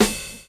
Snare (20).wav